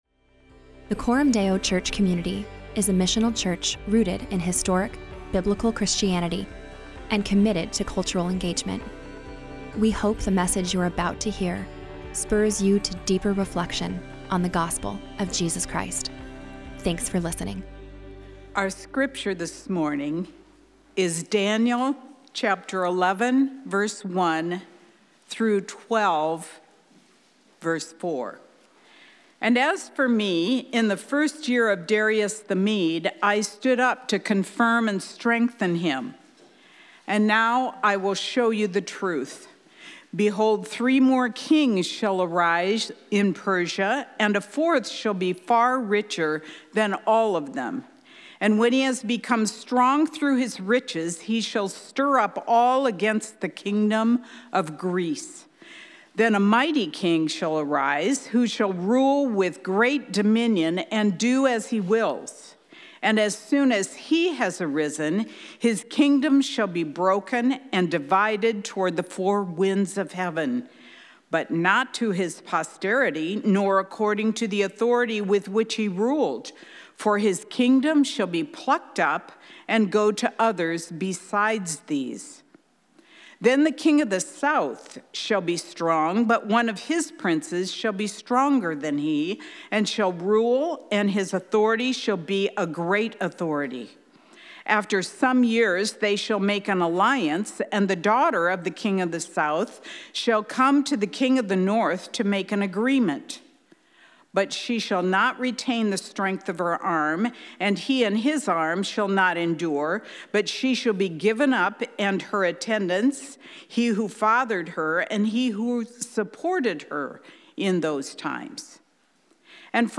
Weekly sermons from Coram Deo Church in Omaha, NE.